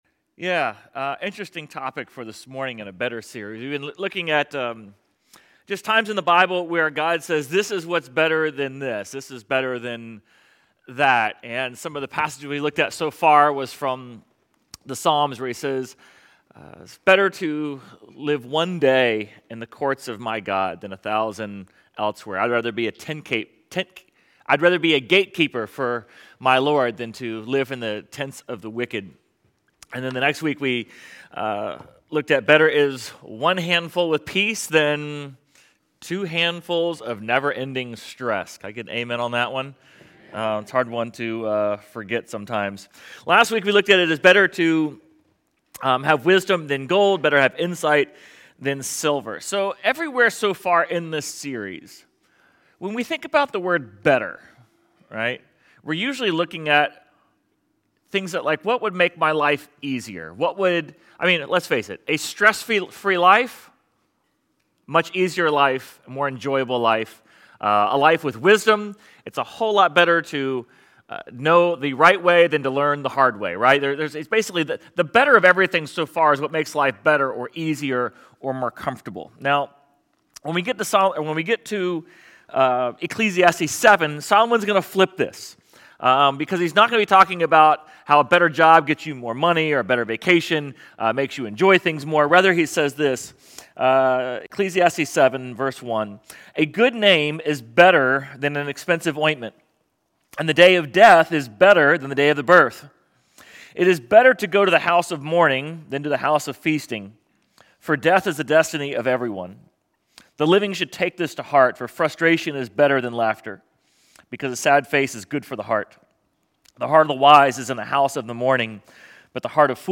Sermon Audio/Video | Essential Church
Sermon_9.14.25.mp3